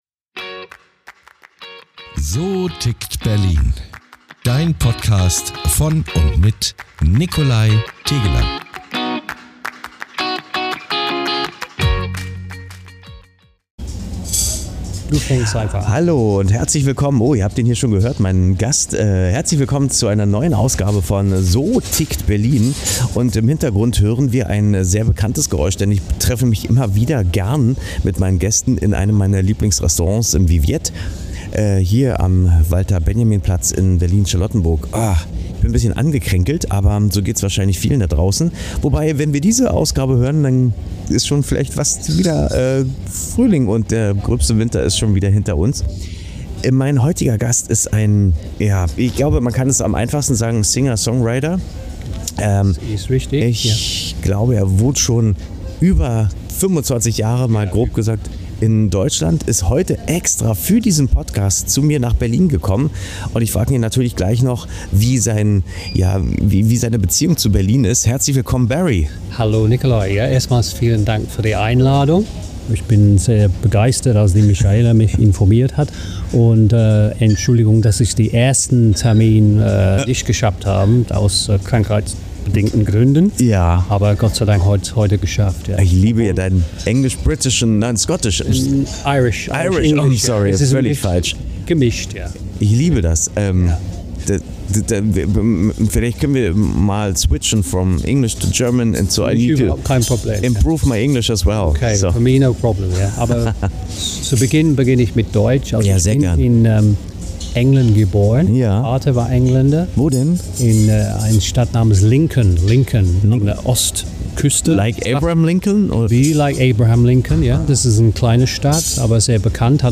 Ein ehrliches, musikalisches Gespräch über Identität, Haltung und die Kraft von Musik, Menschen zu verbinden.